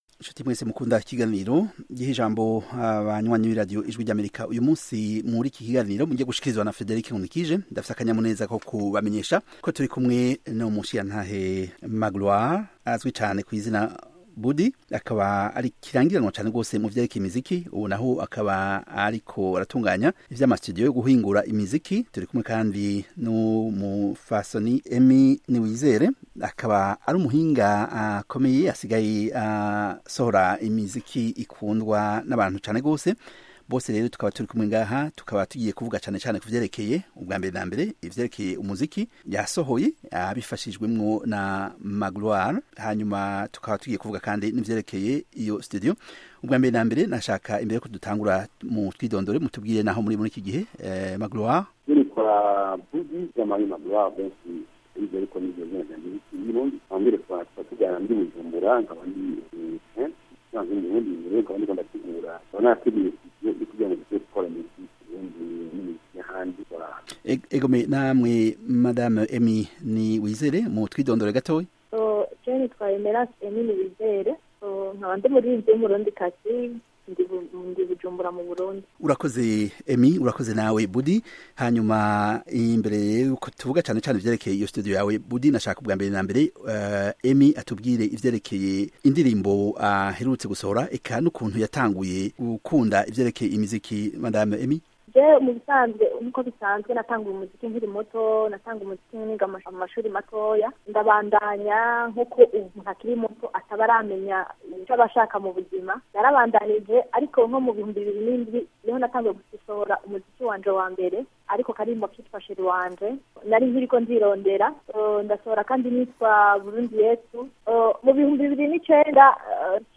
mu kiganiro